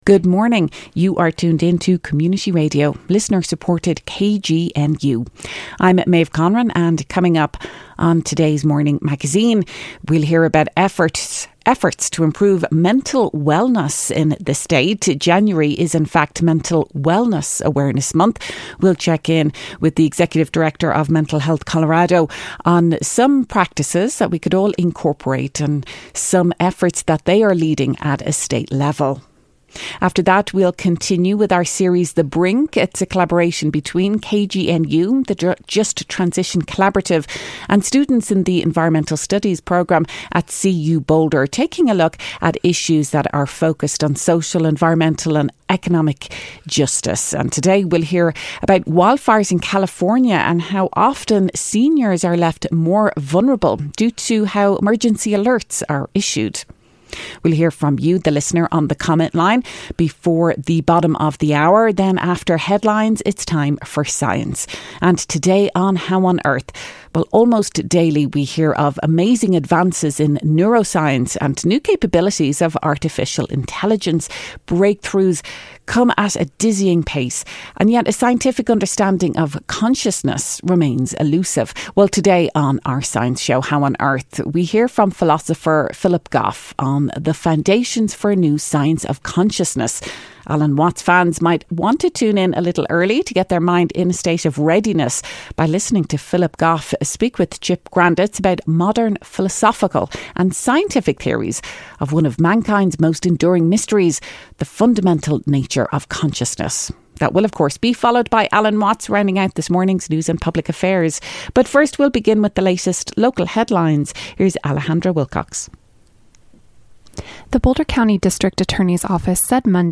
The Morning Magazine features local news headlines, stories and features and broadcasts on KGNU Monday through Friday 8.04-8.30am.